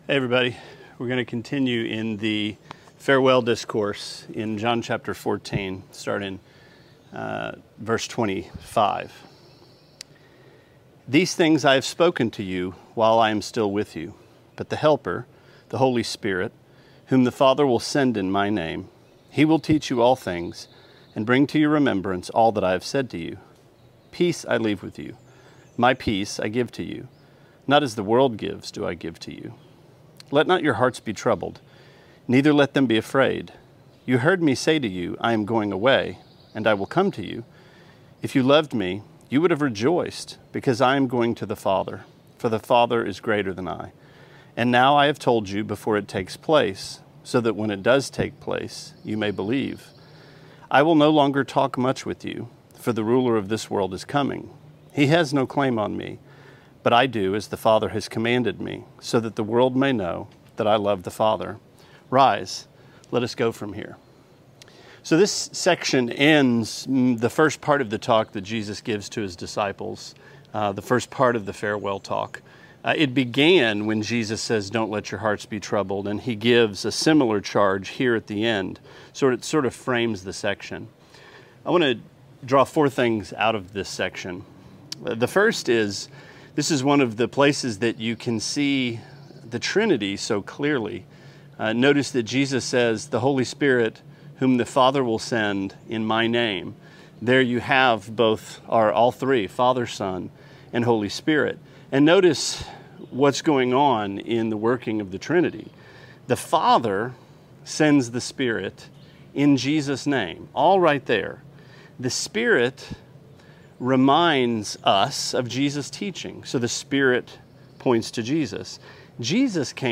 Sermonette 5/23: John 14:25-31: Peace